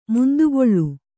♪ mundugoḷḷu